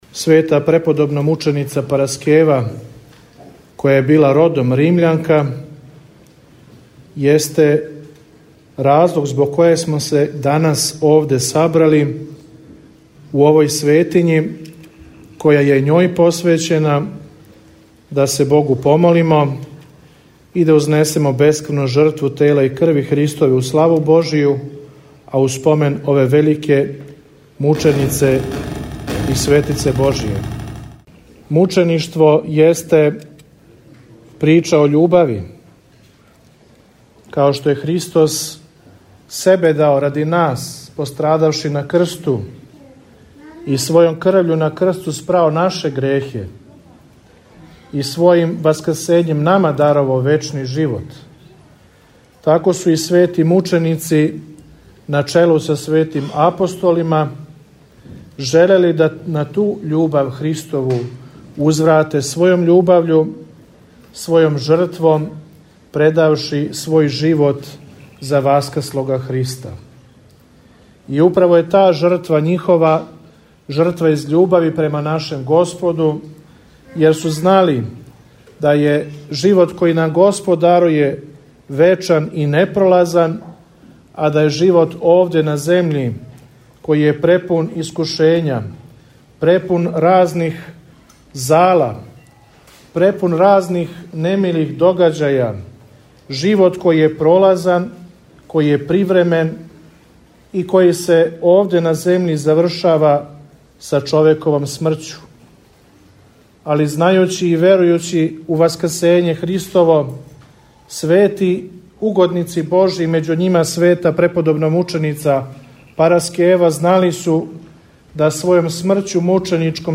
У суботу, 8. августа 2020. године, Његово Преосвештенство Епископ нишки Г. Г. Арсеније служио је Свету архијерејску Литургију у храму посвећеном Божијој угодници преподобномученици Параскеви – Римљанки у селу Рудару.
Звучни запис беседе Верни народ се заједно са својим свештеницима окупио у порти храма како би дочекали свог Архијереја и узели благослов пред почетак службе.